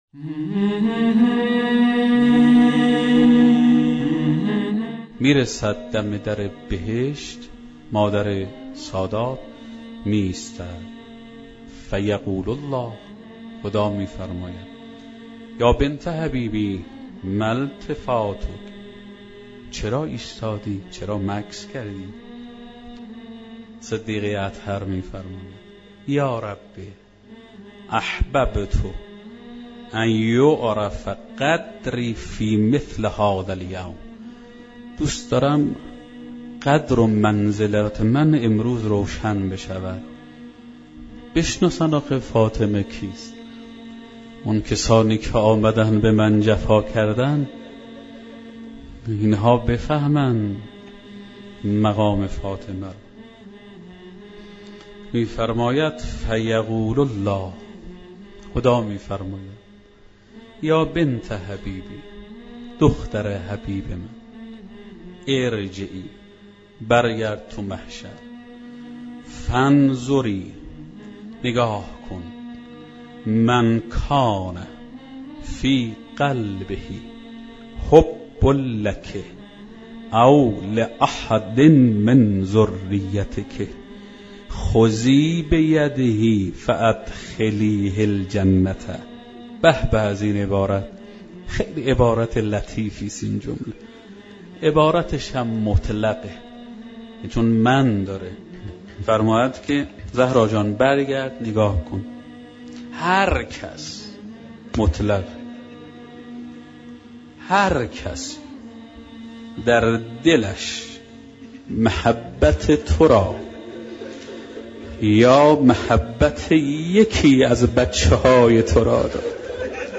سخنرانی شنیدنی درباره مقام حضرت فاطمه زهرا(س